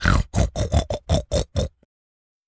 pig_sniff_04.wav